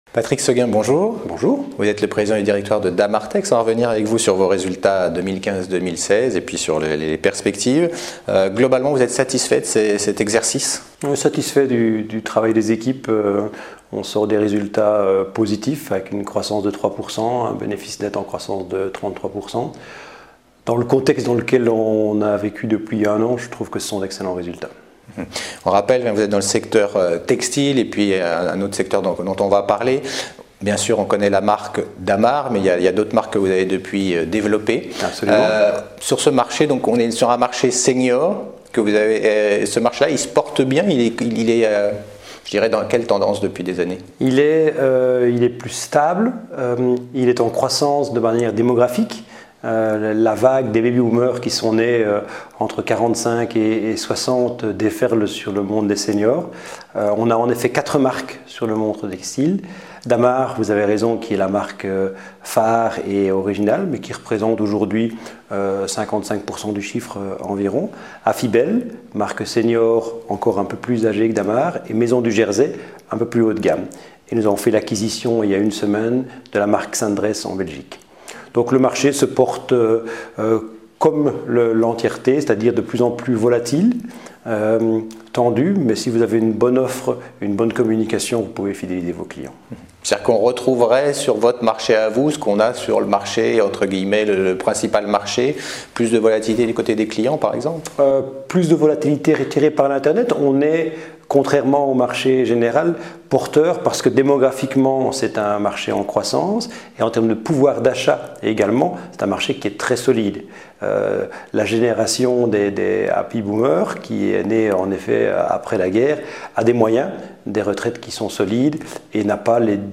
Nous parlons de la stratégie de l’entreprise et de ses perspectives avec mon invité